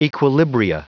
Prononciation du mot equilibria en anglais (fichier audio)
equilibria.wav